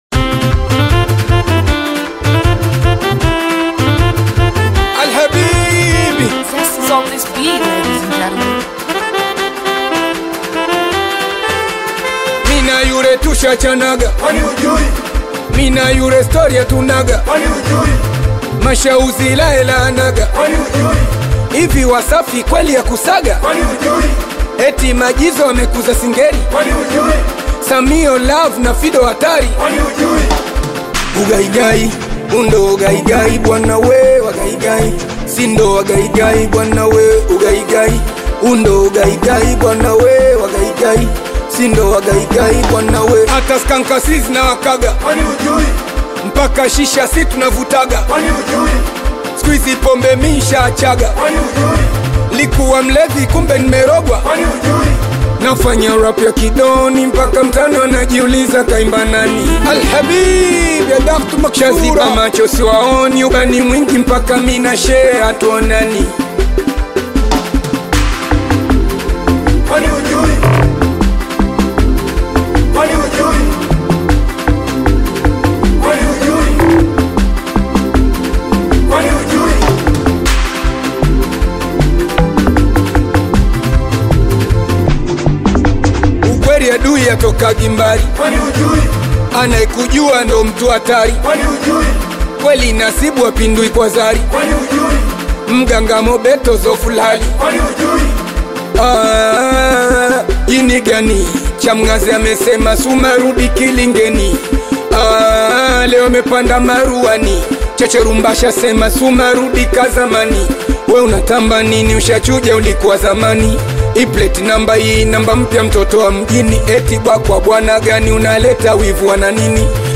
Tanzanian veteran bongo flava artist singer
African Music